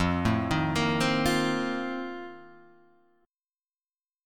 F11 chord {1 0 1 3 1 1} chord